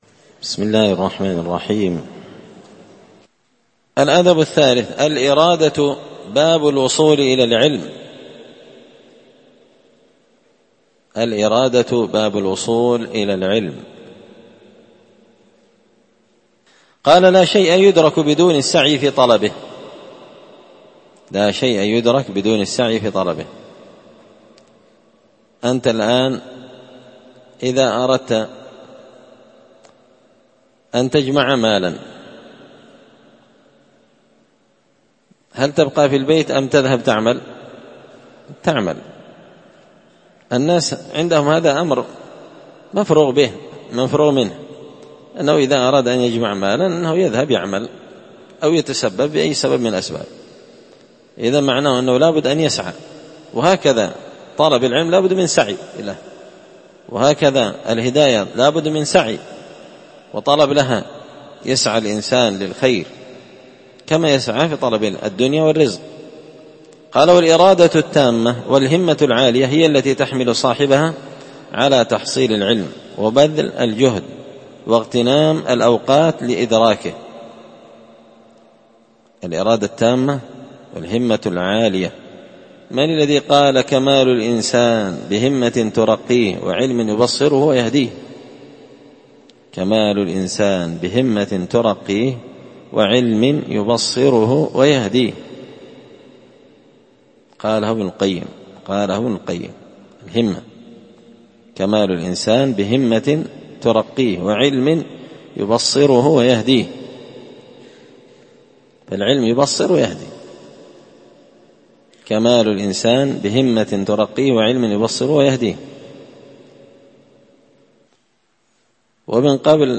الدرس الرابع (4) الأدب الثالث الإرادة باب الوصول إلى العلم